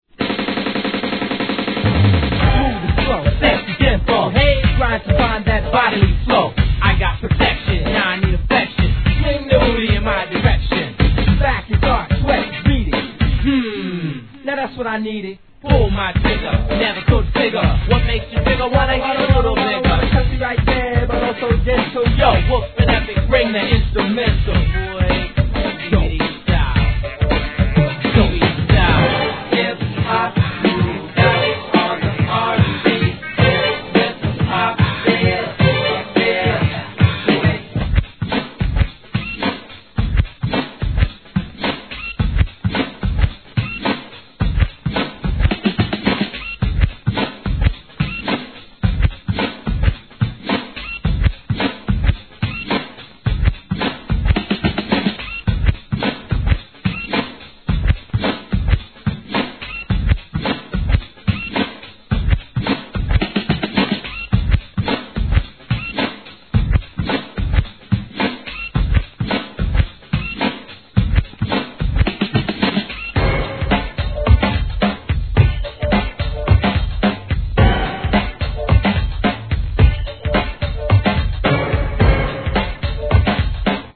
HIP HOP/R&B
FUNKYなNEW JACK SWING決定盤!!